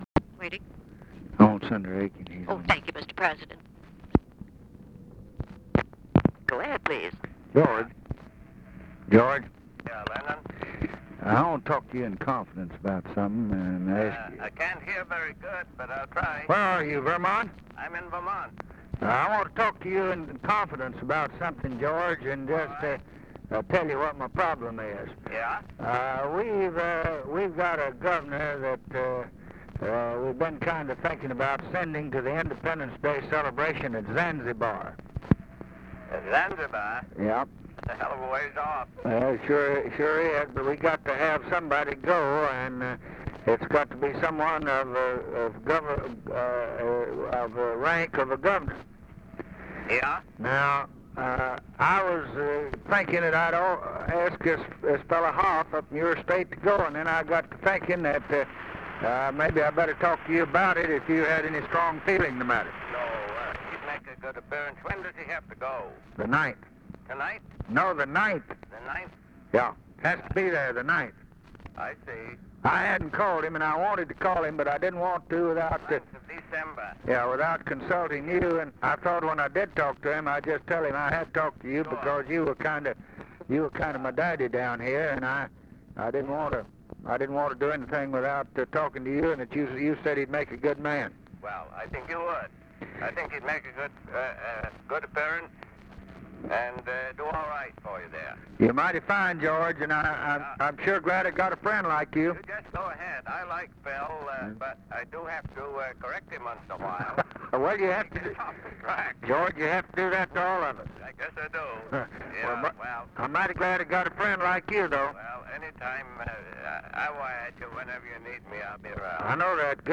Conversation with GEORGE AIKEN, November 30, 1963
Secret White House Tapes | Lyndon B. Johnson Presidency Conversation with GEORGE AIKEN, November 30, 1963 Rewind 10 seconds Play/Pause Fast-forward 10 seconds 0:00 Download audio Previous Conversation with WILLIAM MCC.